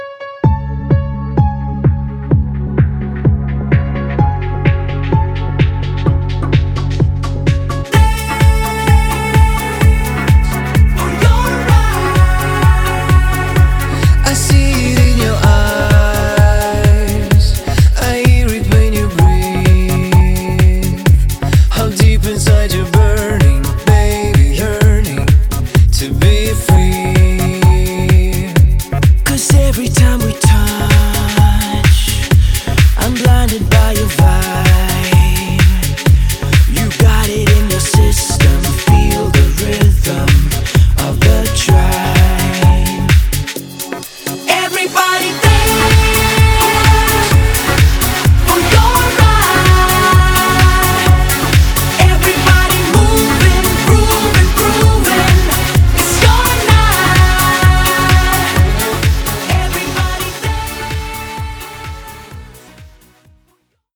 • Качество: 320, Stereo
красивые
мелодичные
пианино